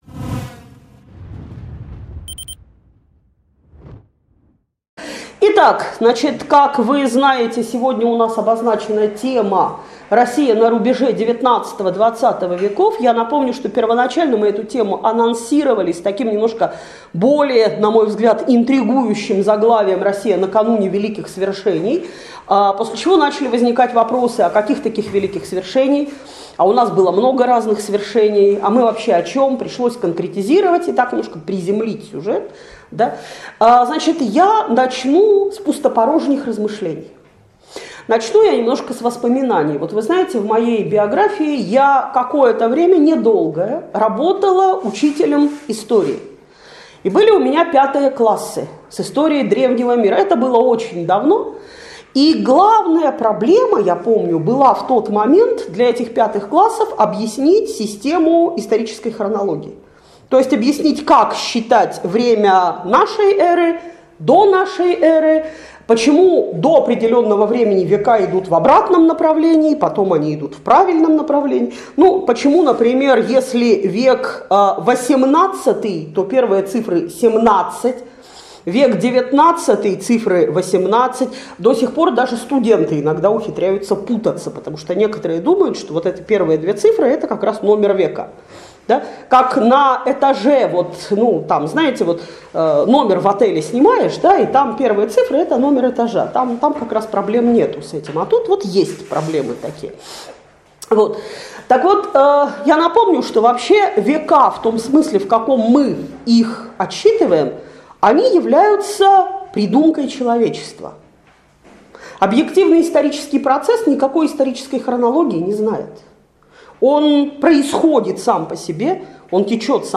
Лекция раскрывает основные черты российского общества после отмены крепостного права, описывает особенности жизни крестьян и помещиков после реформы.